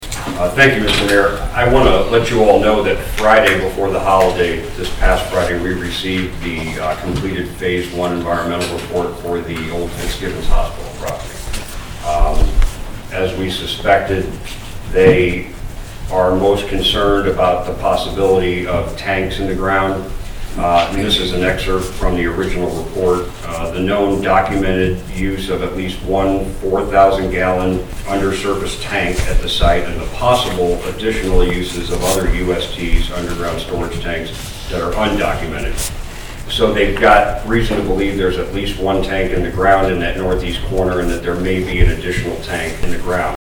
Marshall City Administrator J.D. Kehrman gave an update on the phase one environmental study done at the Old Fitzbibbon Hospital property during the Marshall City Council meeting on Wednesday, July 5.